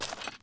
脚踩草地2zth070522.wav
通用动作/01人物/01移动状态/06落叶地面/脚踩草地2zth070522.wav
• 声道 單聲道 (1ch)